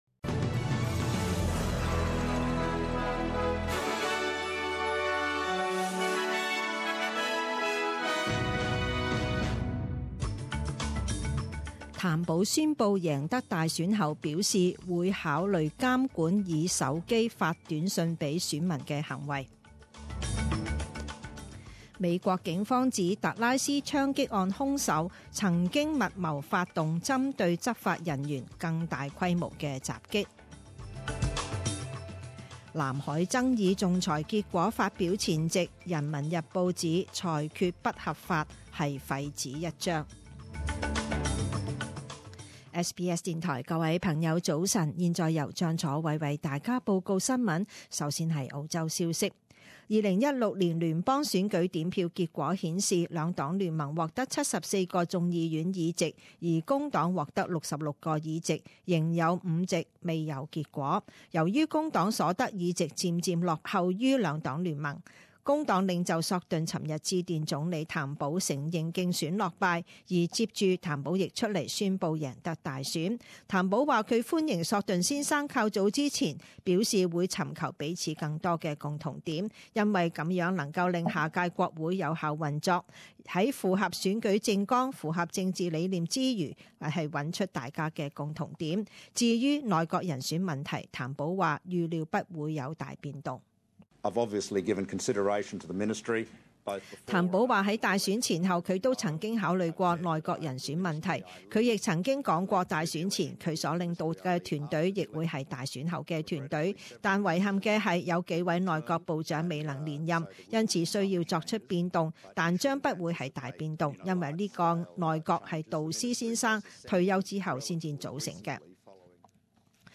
Morning news bulletin